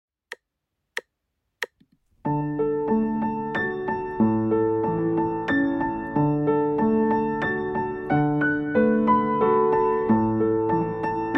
II część: 92 BMP
Nagrania dokonane na pianinie Yamaha P2, strój 440Hz
piano